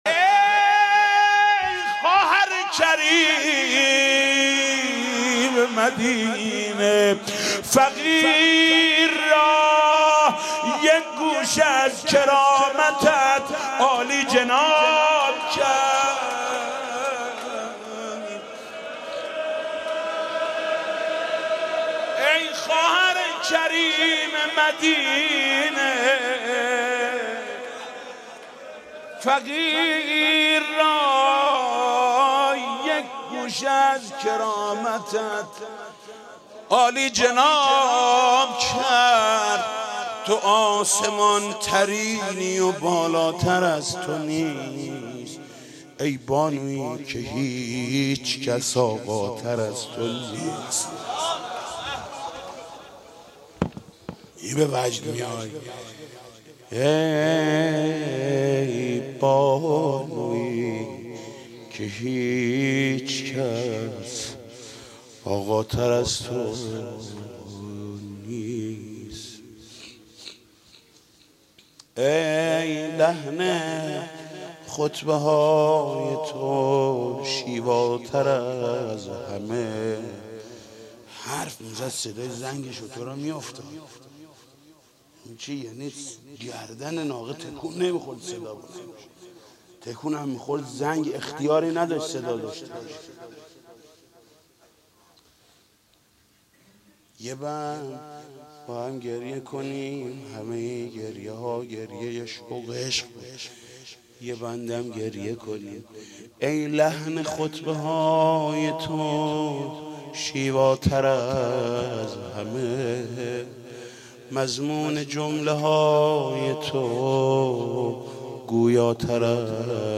مدح.mp3